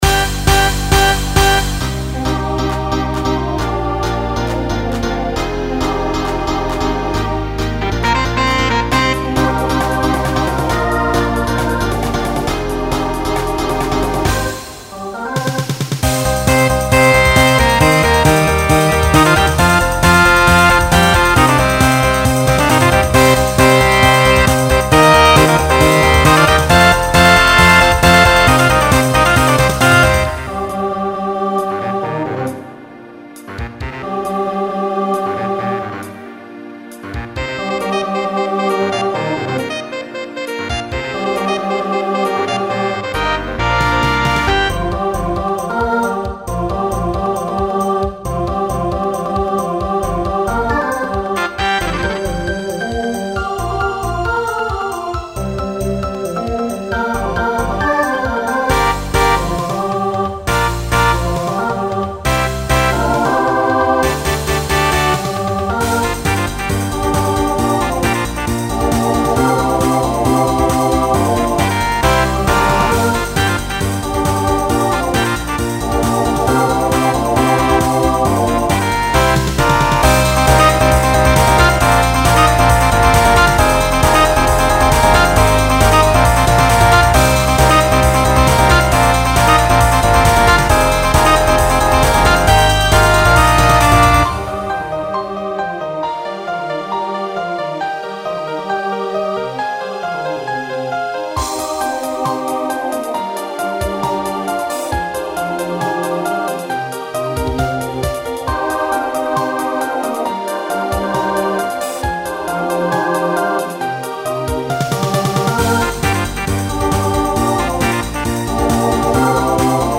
Broadway/Film , Pop/Dance
Yes Yes No Maybe Show Function Closer Voicing SATB